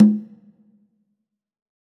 CONGA 36.wav